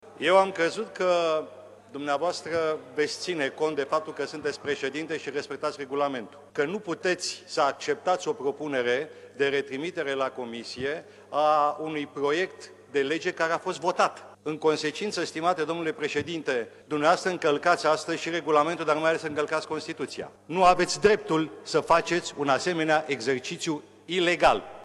Situația a generat dispute aprige astăzi în plenul Camerei.
Deputatul Eugen Nicolaescu a declarat că este imposibil ca o lege să fie votată de două ori.